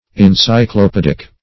Encyclopedic \En*cy`clo*ped"ic\, Encyclopedical